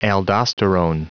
Prononciation du mot aldosterone en anglais (fichier audio)